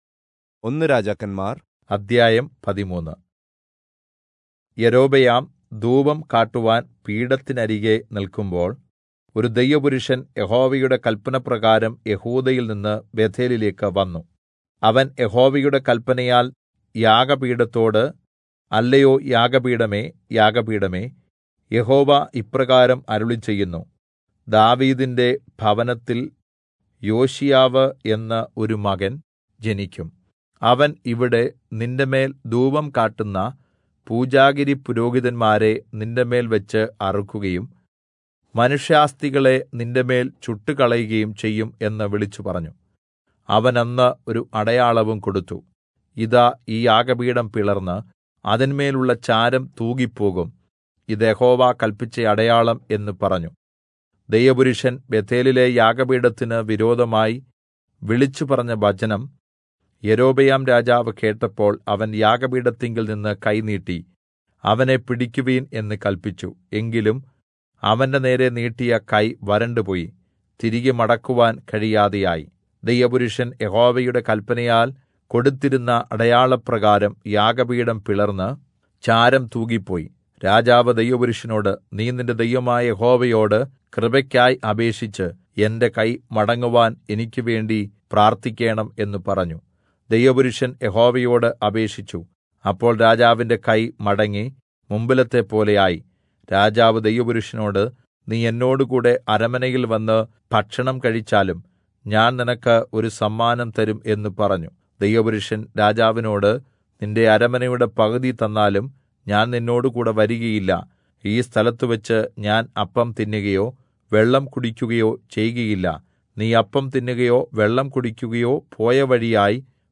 Malayalam Audio Bible - 1-Kings 19 in Irvml bible version